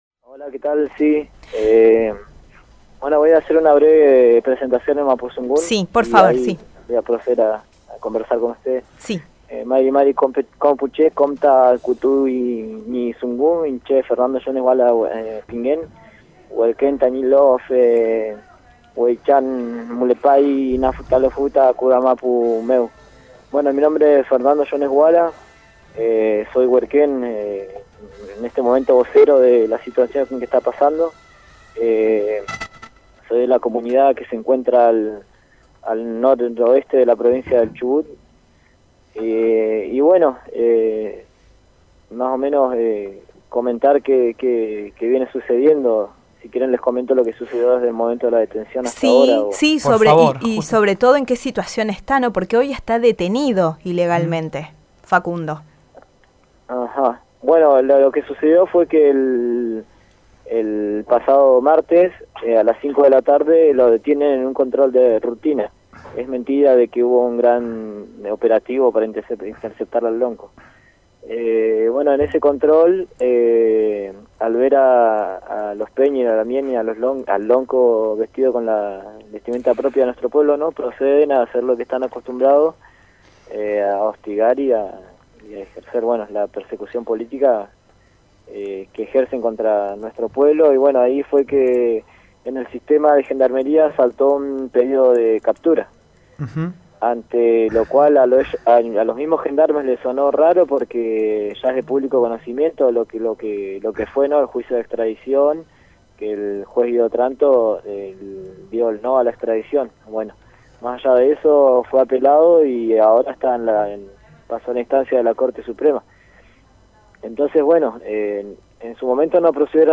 converso con La flecha, sobre la situación del lonko.